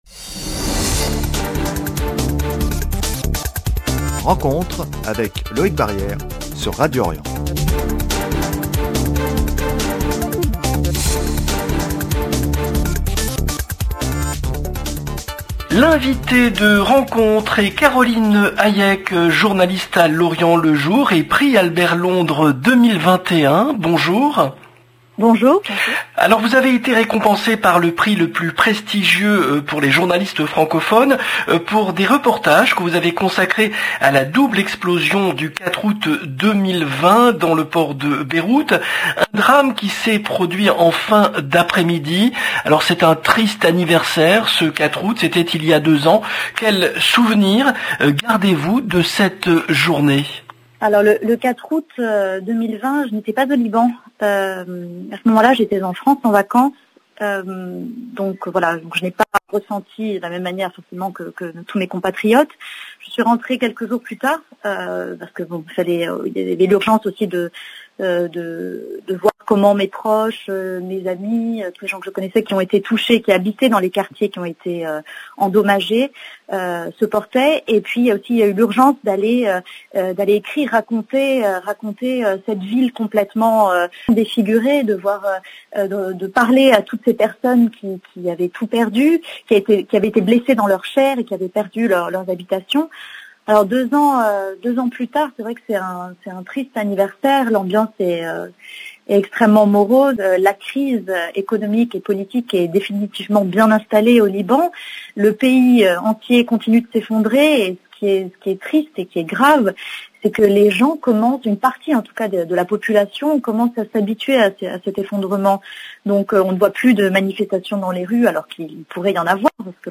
Entretien consacré au 2e anniversaire de la double explosion du port de Beyrouth.